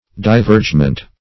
Divergement \Di*verge"ment\, n.